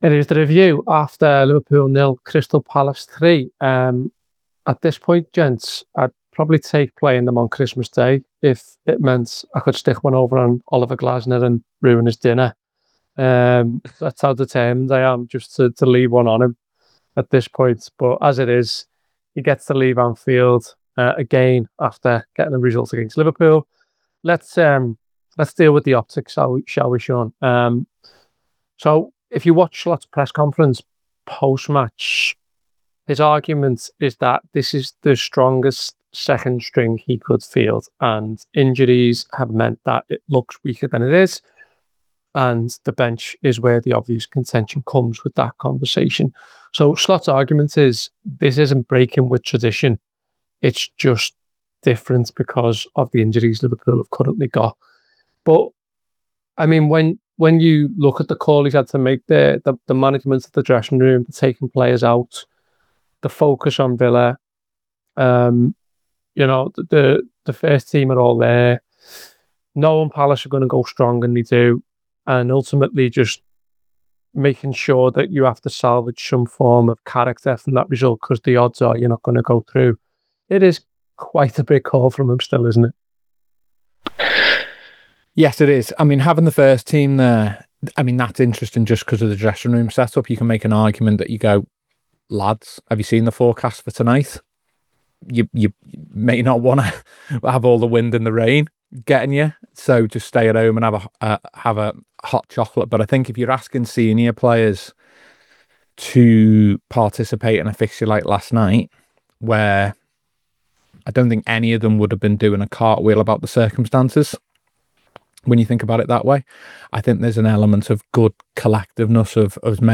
Below is a clip from the show – subscribe to The Anfield Wrap for more review chat…